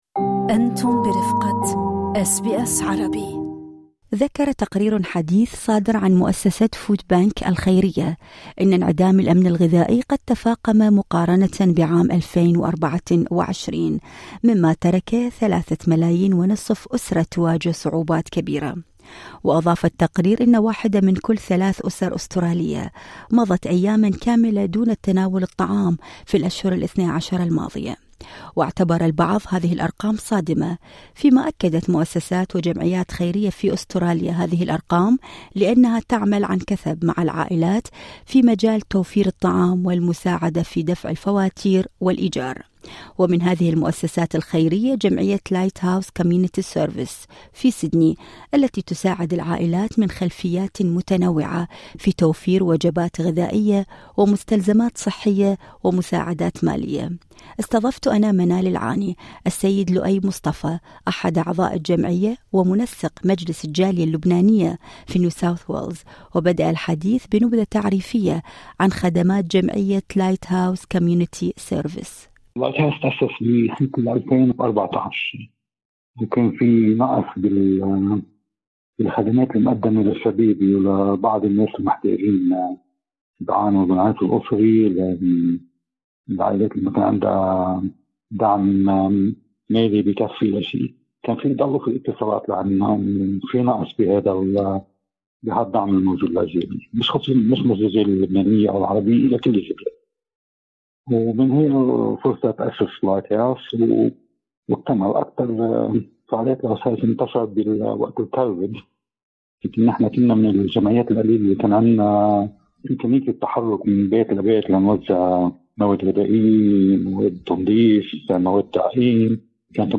التفاصيل في اللقاء الصوتي اعلاه